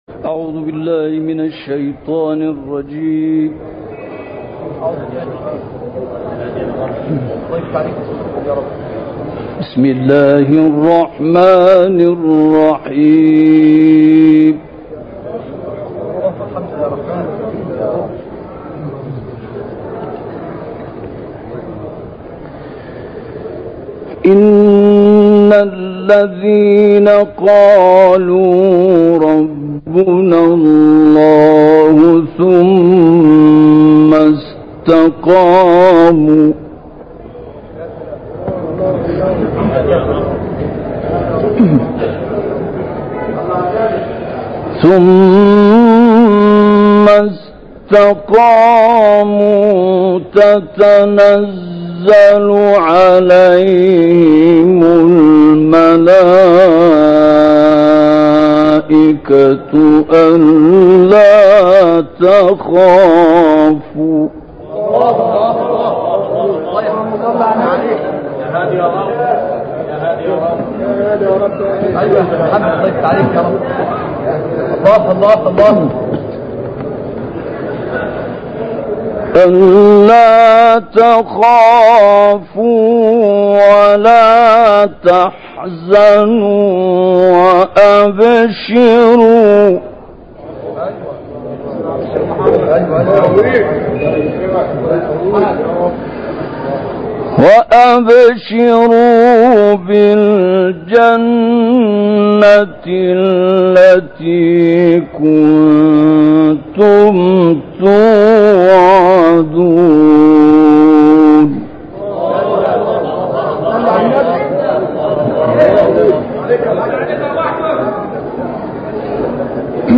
تلاوت سوره فصلت